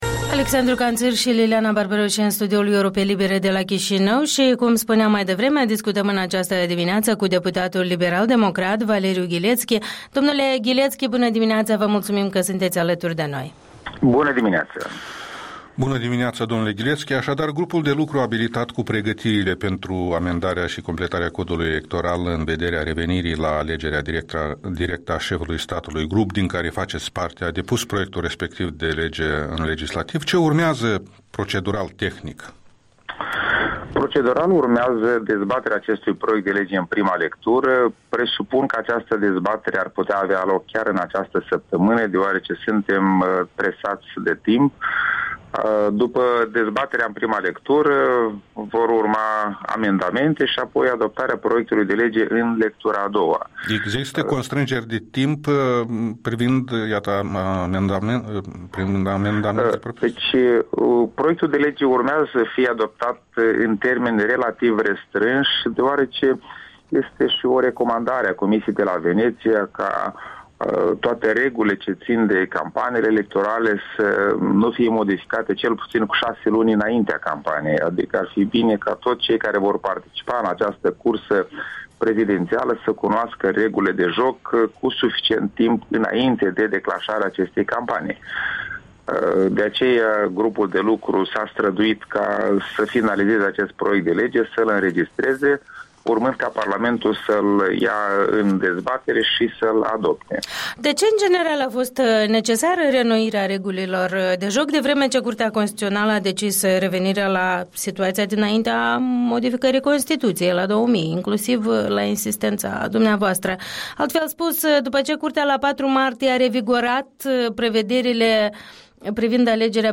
Interviul matinal cu deputatul liberal-democrat despre aspecte ale modificării Codului Electoral.
Interviul dimineții cu deputatul Valeriu Ghilețchi